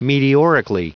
Vous êtes ici : Cours d'anglais > Outils | Audio/Vidéo > Lire un mot à haute voix > Lire le mot meteorically
Prononciation du mot : meteorically
meteorically.wav